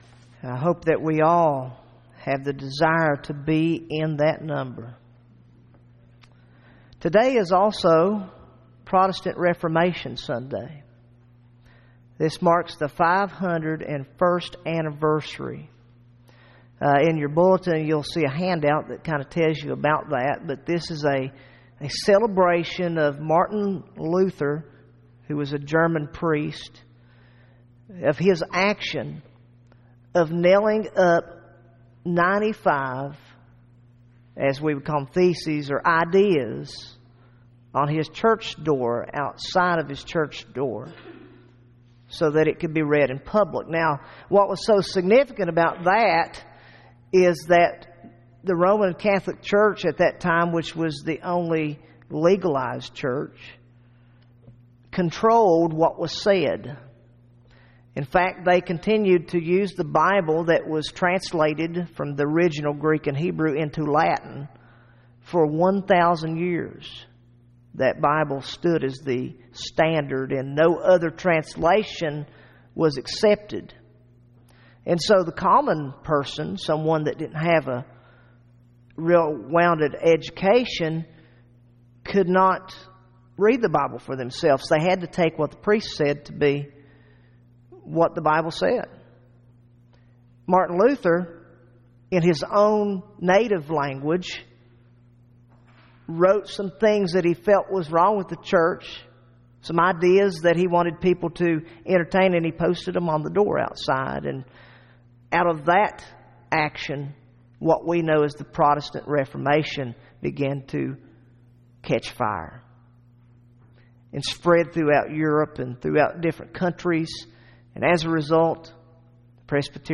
First Presbyterian Church Williamson, WV WORSHIP SERVICES